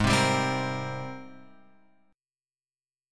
G# Major 9th